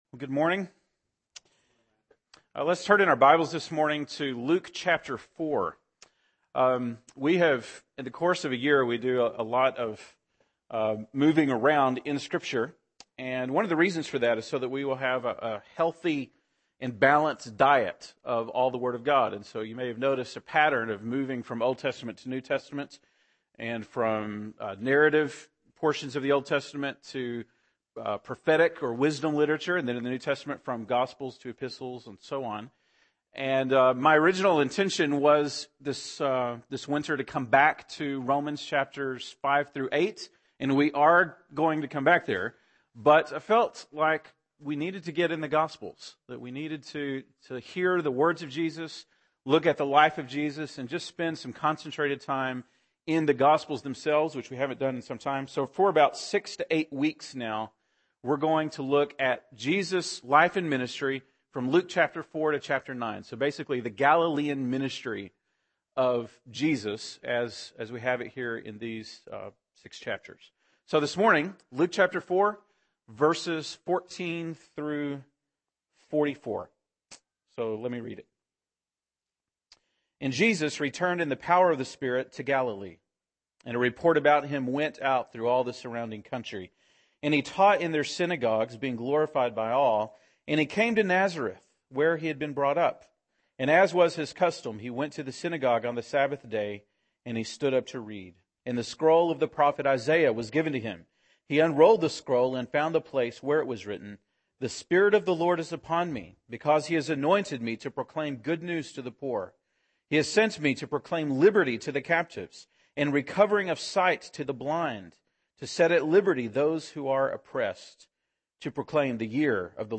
January 29, 2012 (Sunday Morning)